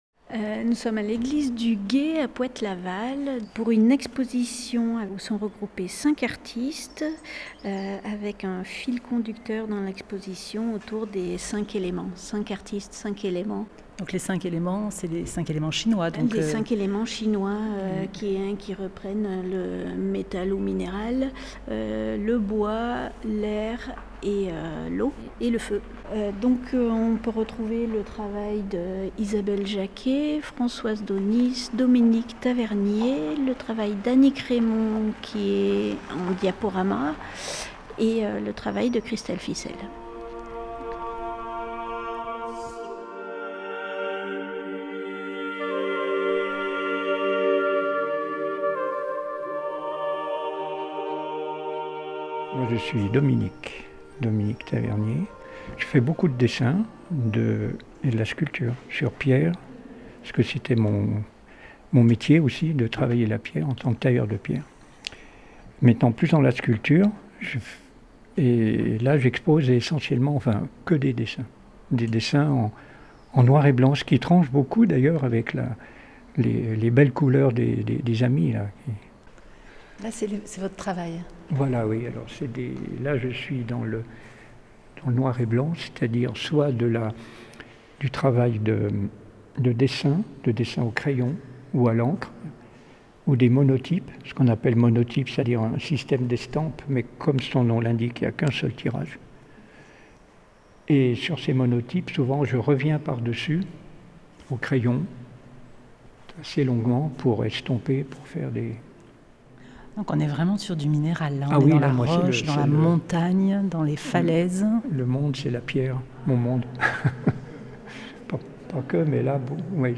Interview, la belle sais'onde
Les deux artistes nous font visiter l’exposition collective visible à l’église du Gué au Poët-Laval, jusqu’au 21 juin.